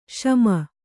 ♪ śama